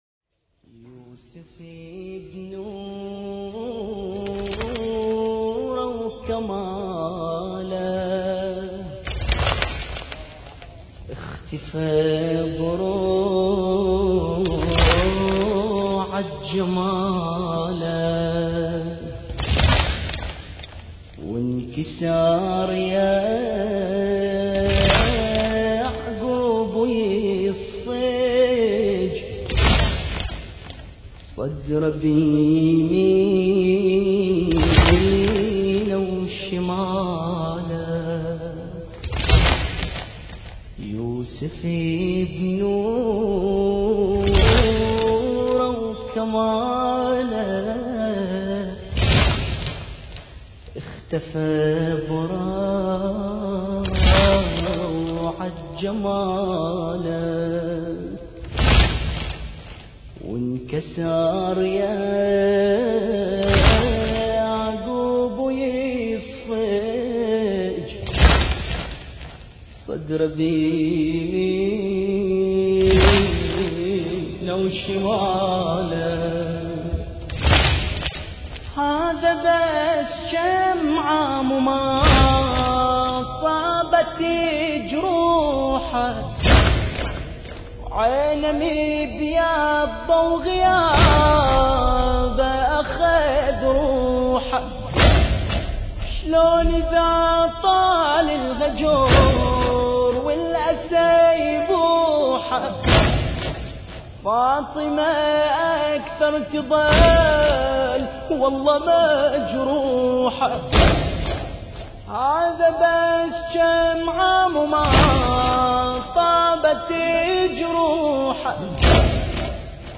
ذكرى شهادة الإمام الحسن العسكري (عليه السلام) ١٤٣٨ هـ